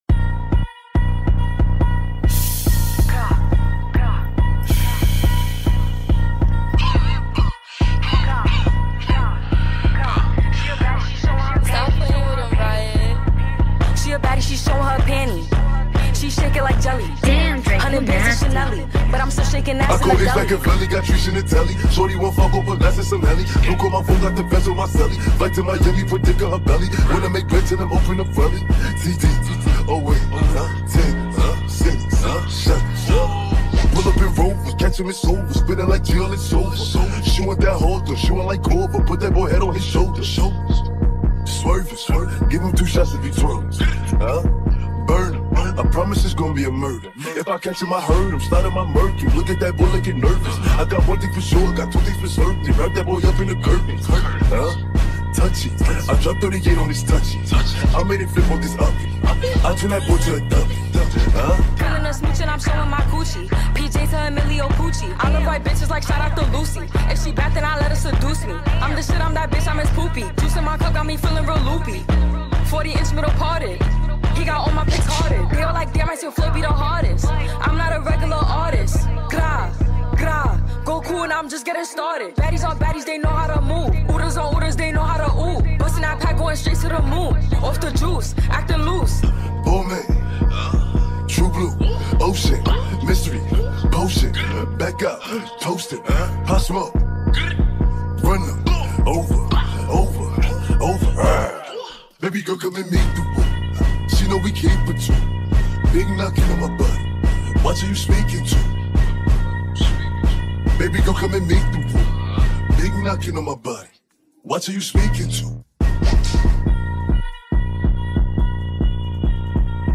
• Качество: 320 kbps, Stereo
Mashup remix